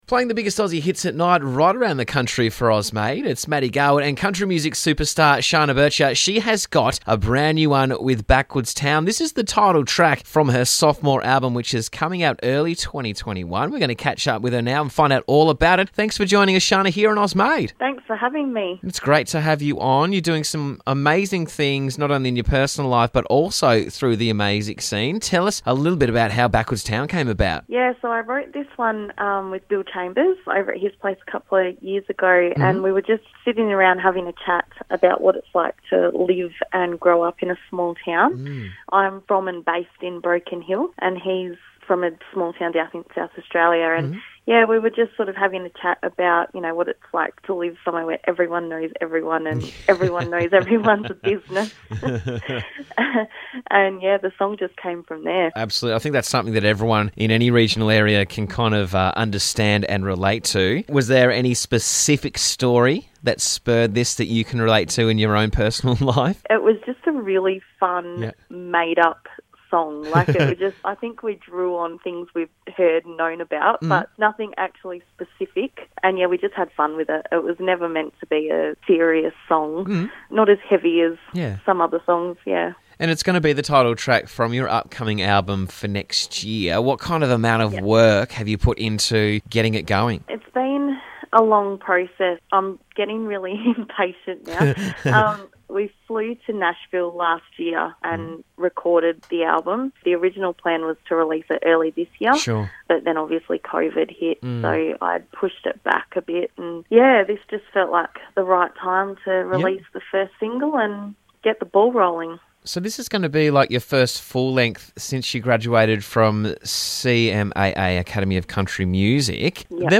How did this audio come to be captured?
The single was recorded at Troubadour House in Nashville